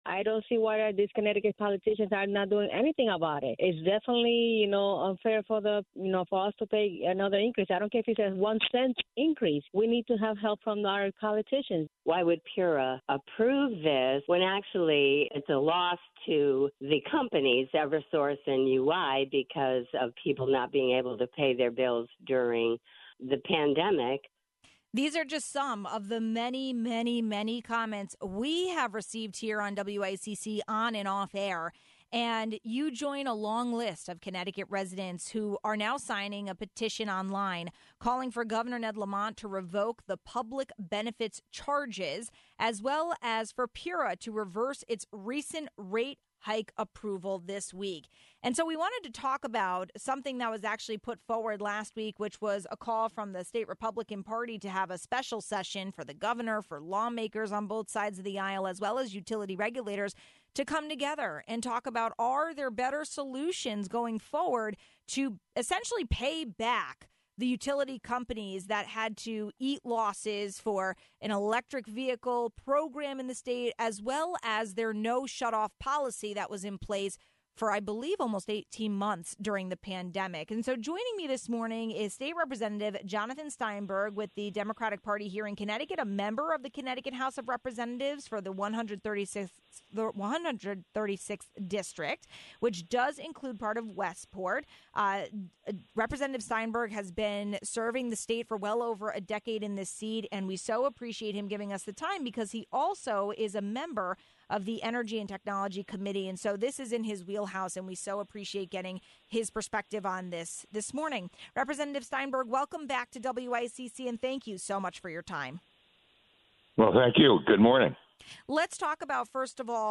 We got perspective from Rep. Jonathan Steinberg, co-chair of the Energy & Technology Committee, about the issue at hand and whether a special session will happen.